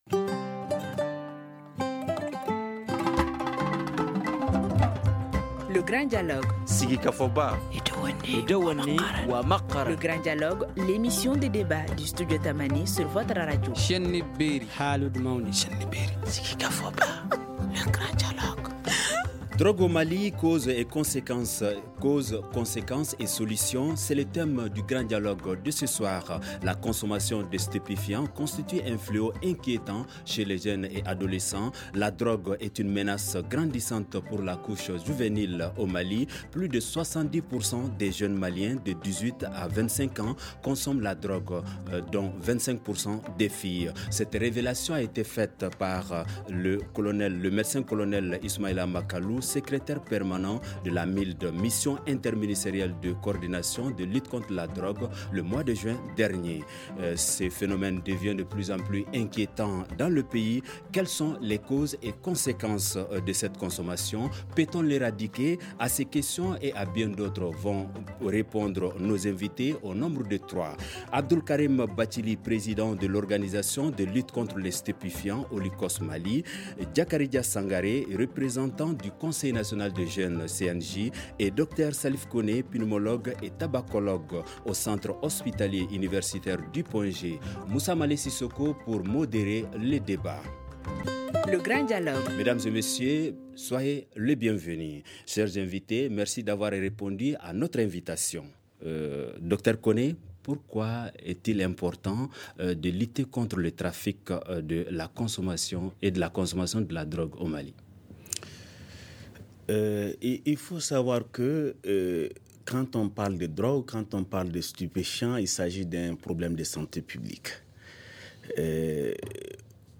Pour en débattre, nous recevons trois invités :